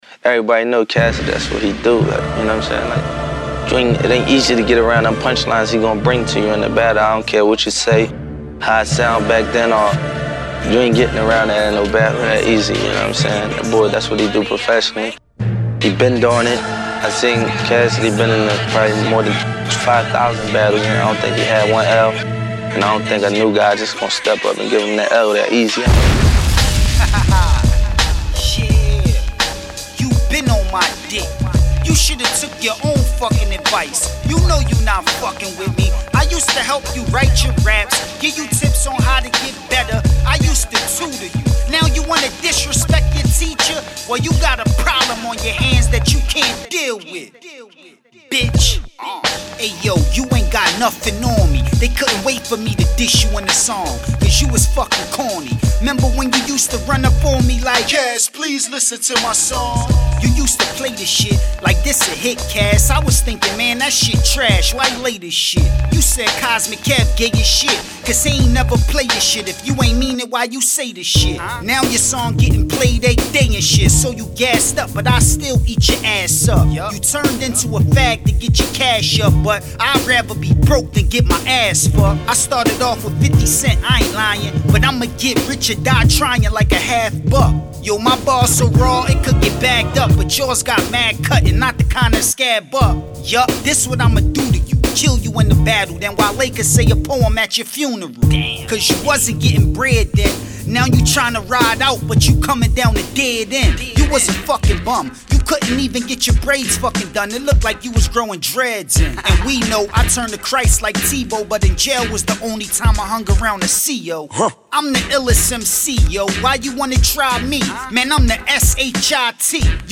over various instrumentals